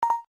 af_success.ogg